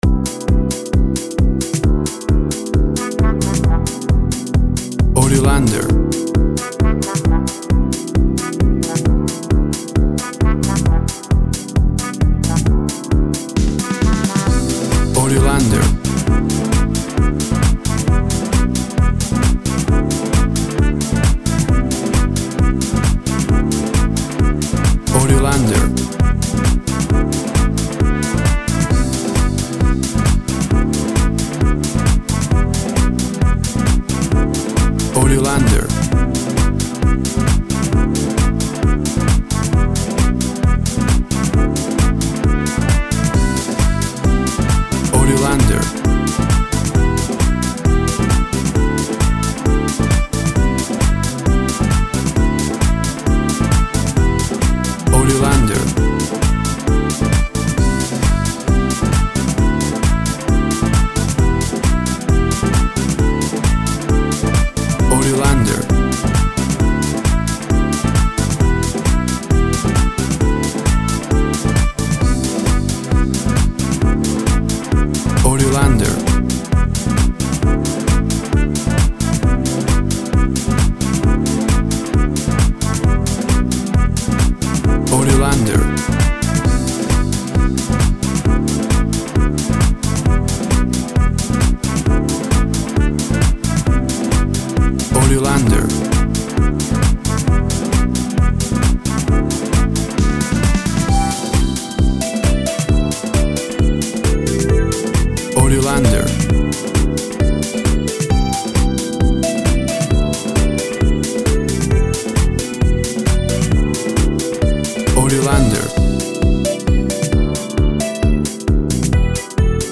Upbeat, groovy, uptempo and funky!
Tempo (BPM) 135